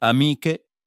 CH = Always "K" sound (the H keeps it hard)
friends (f) ah-MEE-keh